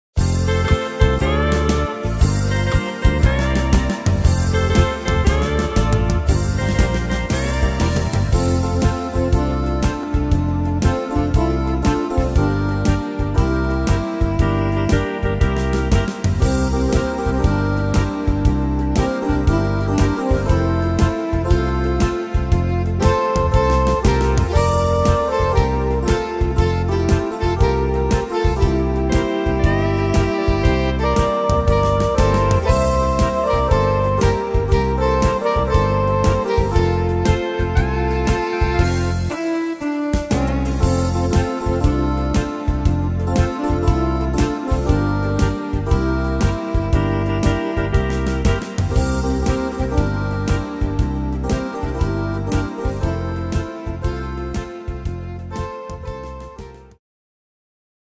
Tempo: 118 / Tonart: F-Dur